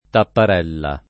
tapparella [ tappar $ lla ]